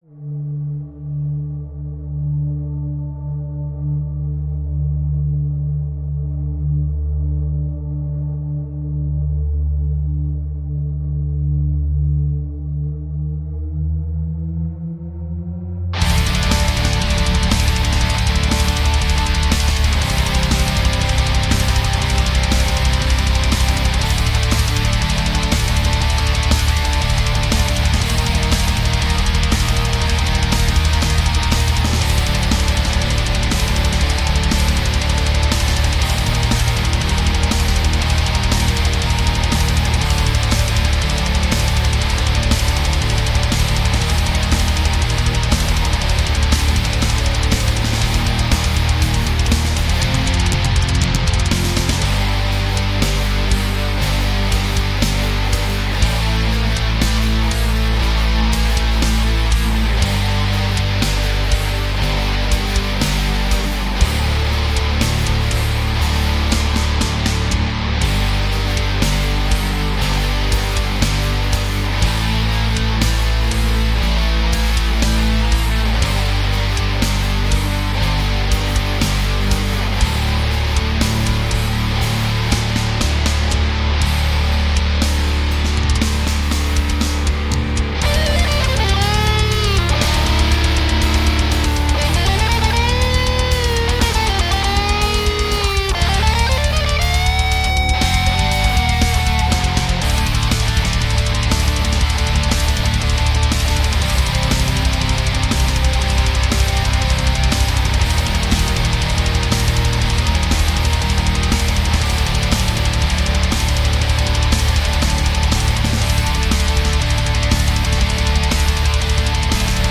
Darkest Hour (Black/Doom Metal)